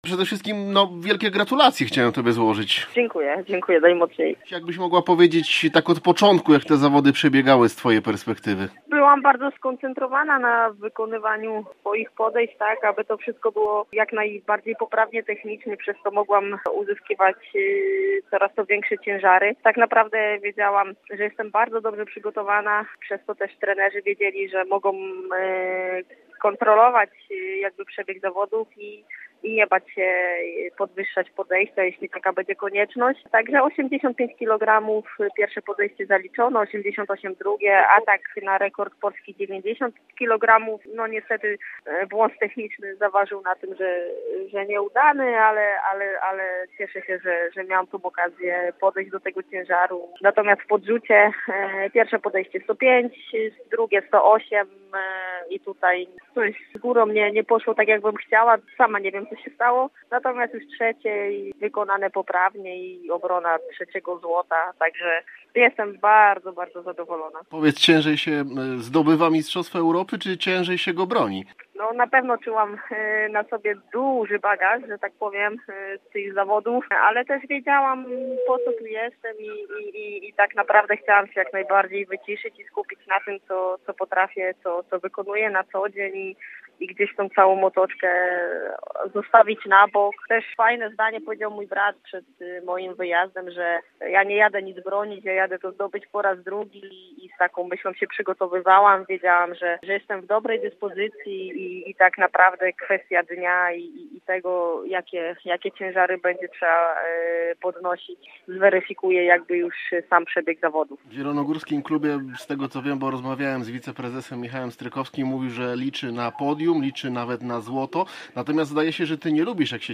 Po zawodach rozmawialiśmy z naszą złotą medalistką.
joanna-lochowska-cala-rozmowa-po-zdobyciu-kolejnego-zlota-me.mp3